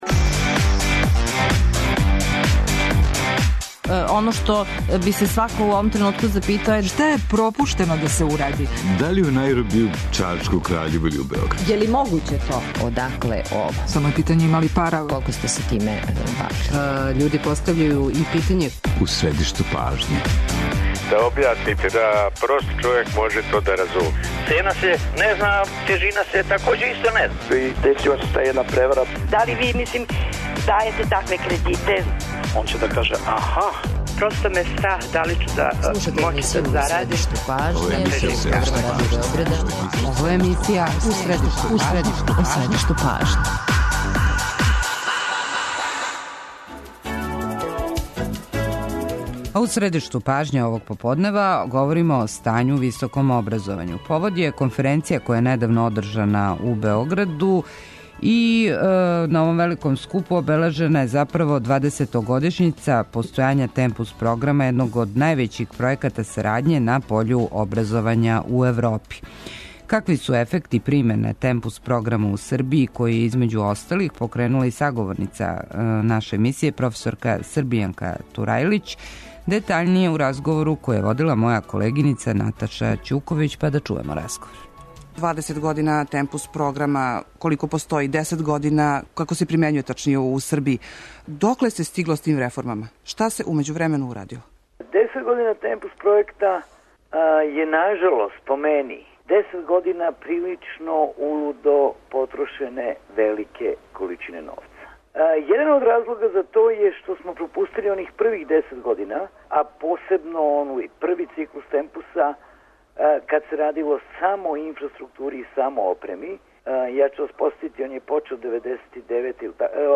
Гост емисије је ректор Београдског Универзитета проф. Бранко Ковачевић.